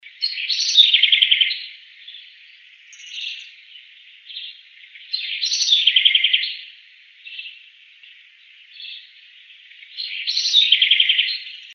Southern House Wren (Troglodytes musculus)
Life Stage: Adult
Location or protected area: Reserva Natural El Destino
Condition: Wild
Certainty: Recorded vocal